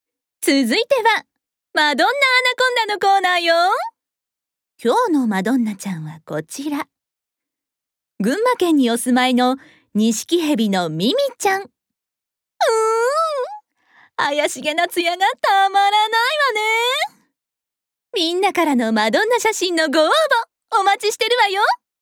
ボイスサンプル
美術番組ナレーション
動物番組ナレーション